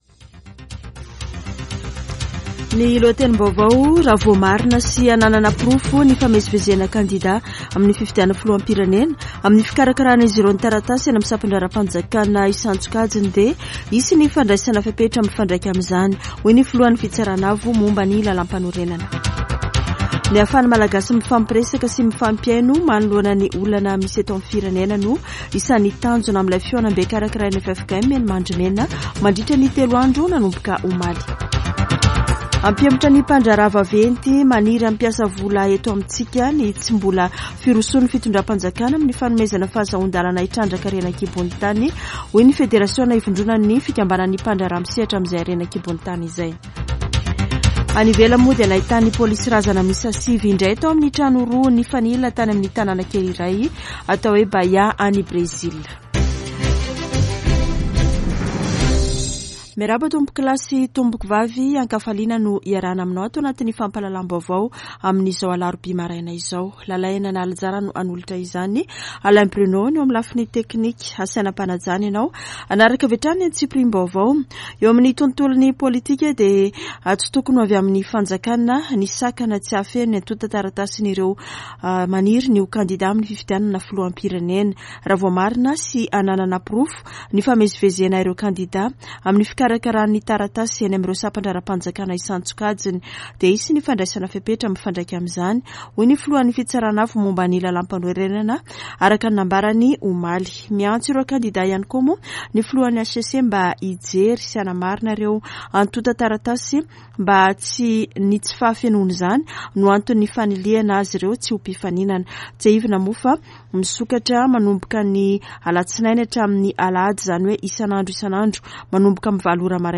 [Vaovao maraina] Alarobia 30 aogositra 2023